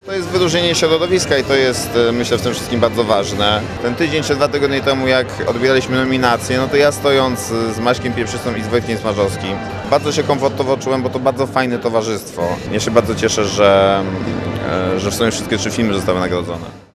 – mówił po gali Matuszyński.